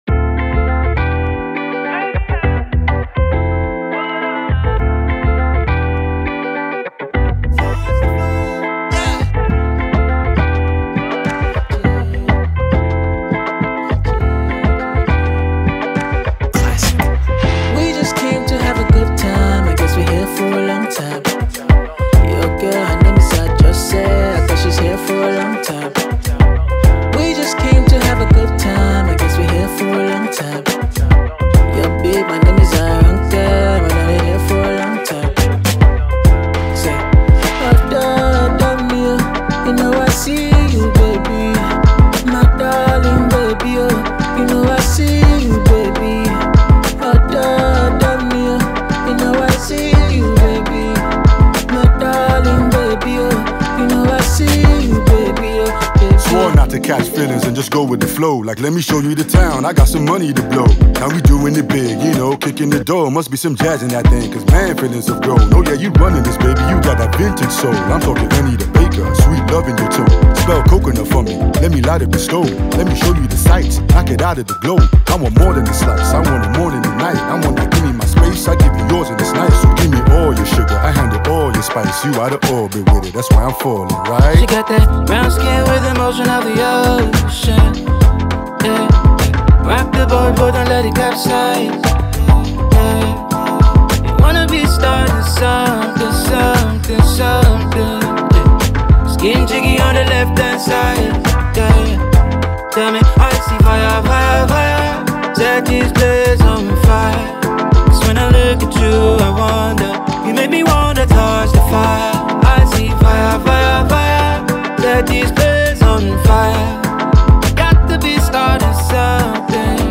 a Nigerian rap duo